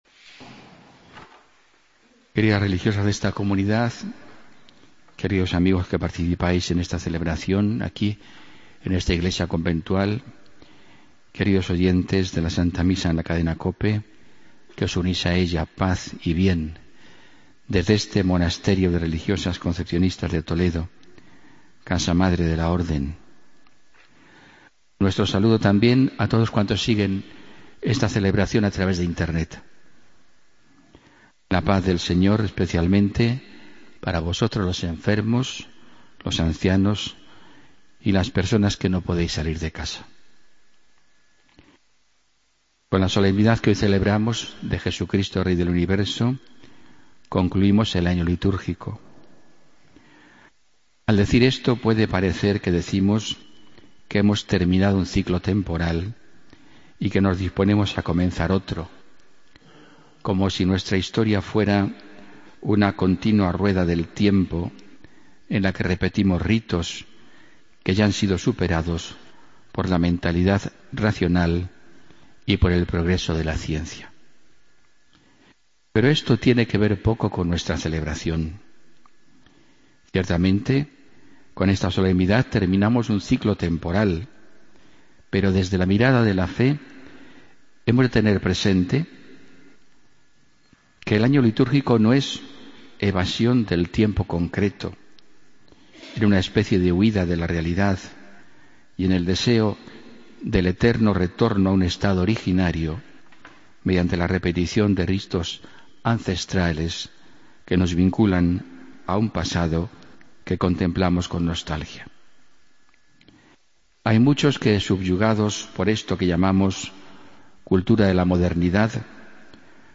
Homilía del domingo 22 de noviembre de 2015